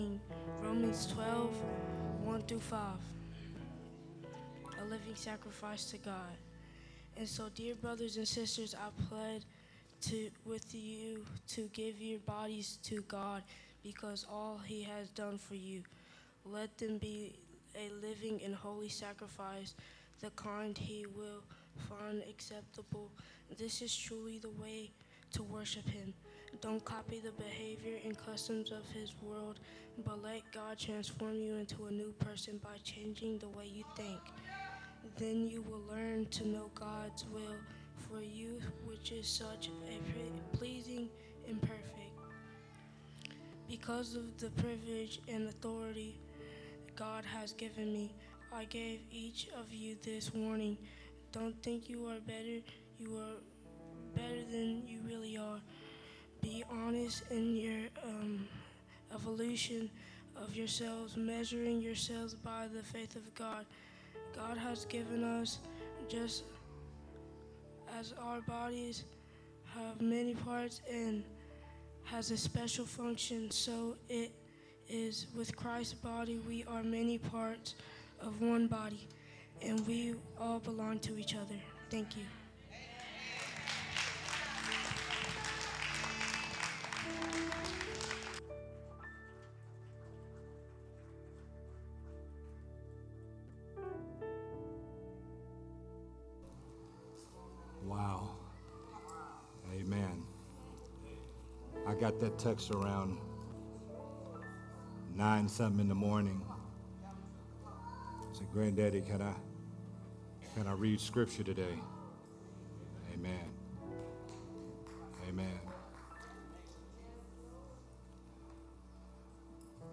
a sermon
Sunday Morning Worship Service